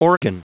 Prononciation du mot organ en anglais (fichier audio)
Prononciation du mot : organ